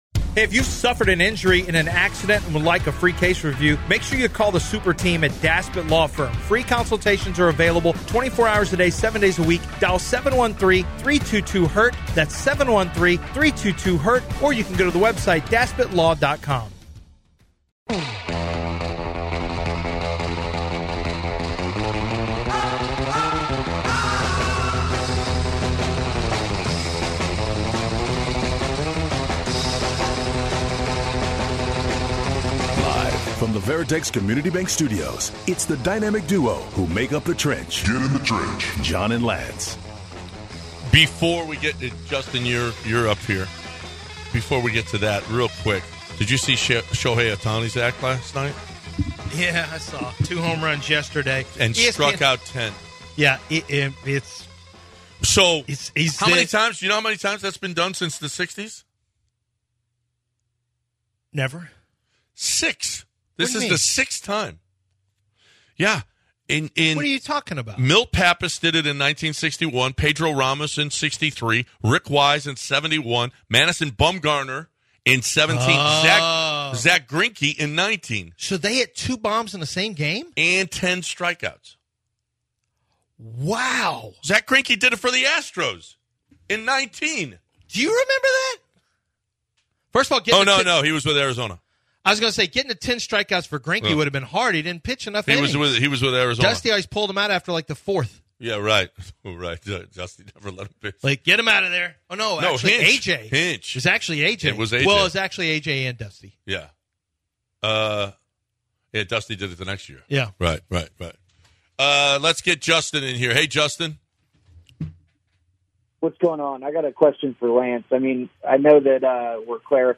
In our final hour, Callers want to know who would win in a game of 1-v-1 in basketball